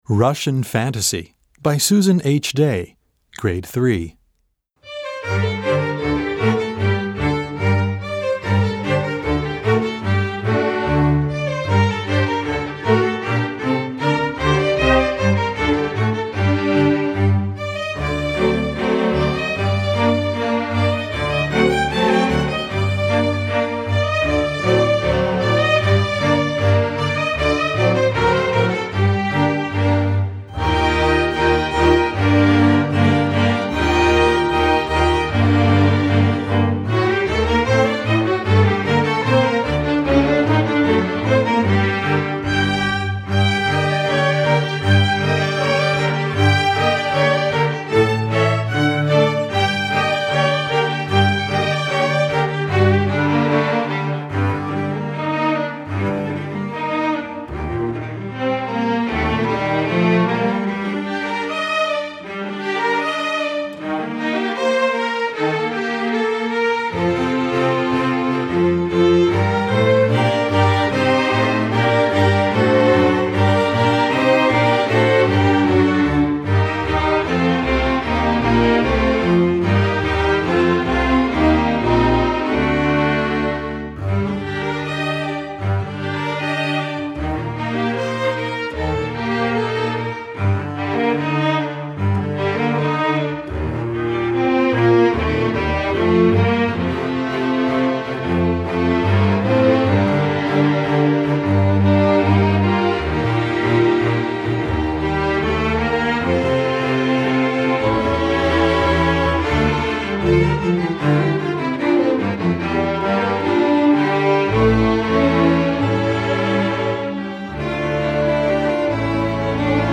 Gattung: Streichorchester
Besetzung: Streichorchester
This lively piece
written in G minor and D minor